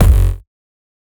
Index of /99Sounds Music Loops/Drum Oneshots/Twilight - Dance Drum Kit/Kicks